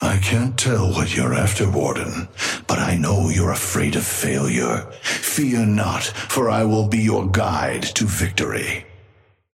Amber Hand voice line - I can't tell what you're after, Warden.
Patron_male_ally_warden_start_05.mp3